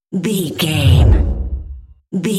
Whoosh deep
Sound Effects
Atonal
dark
futuristic
tension